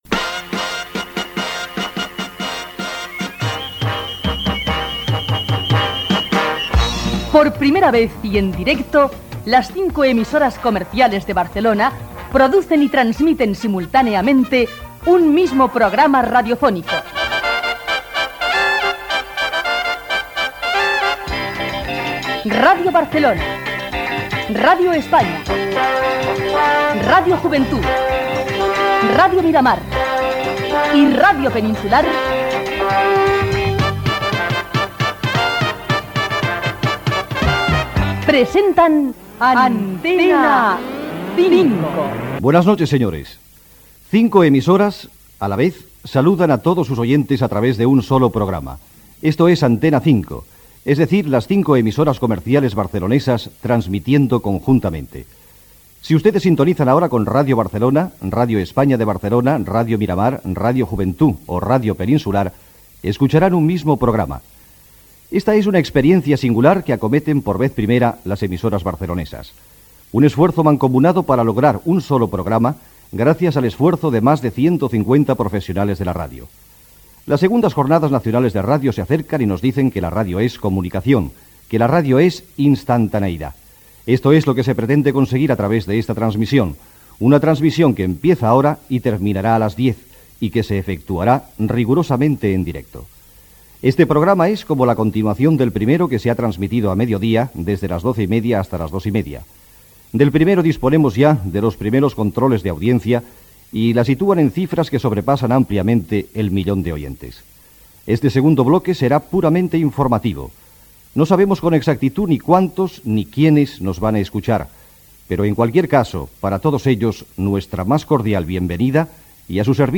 Careta del programa
presentació del programa informatiu del vespre, fet des dels estudis de Ràdio Peninsular de Barcelona.
Info-entreteniment